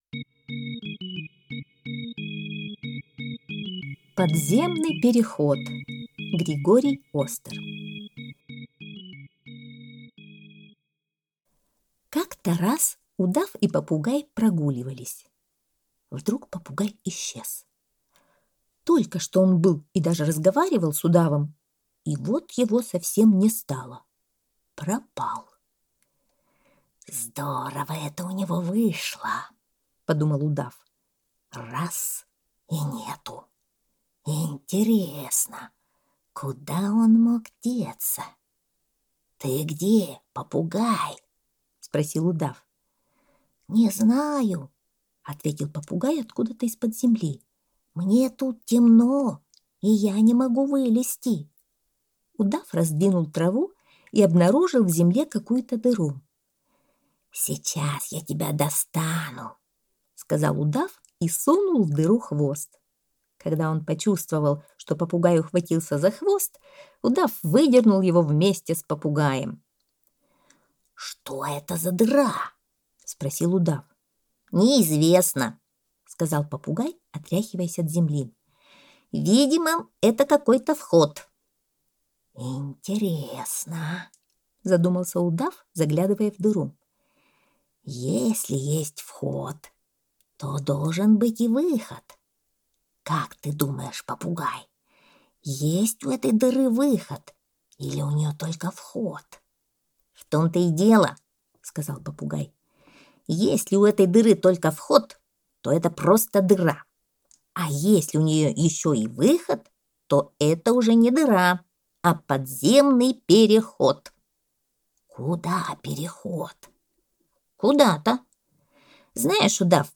Подземный переход - аудиосказка Остера - слушать онлайн